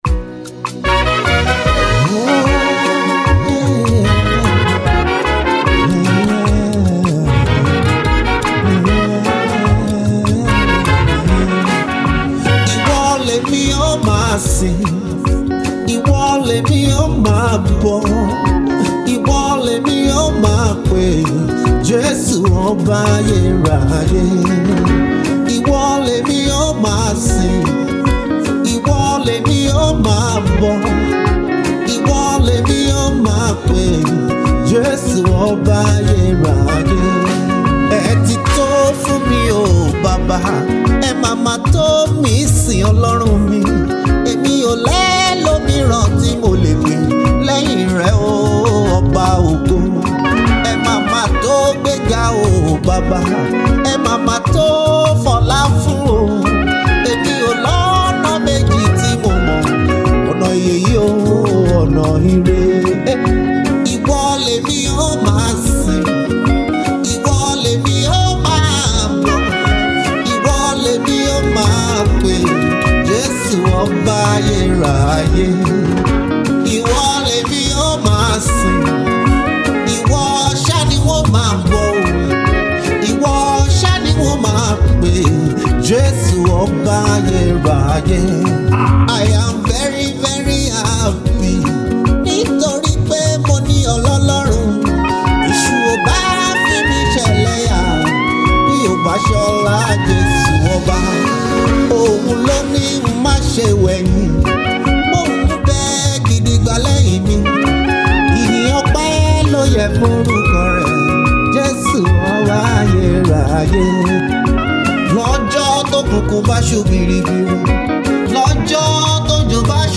Nigerian gospel music minister and prolific songwriter